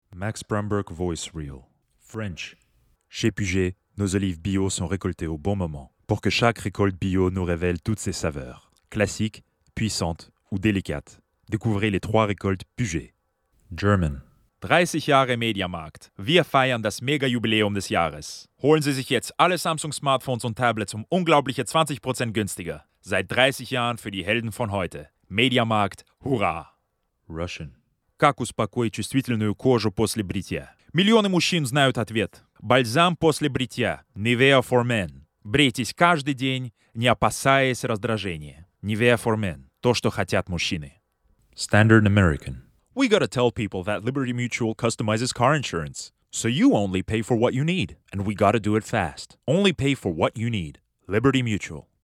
Flawless US accent.
VOICE REEL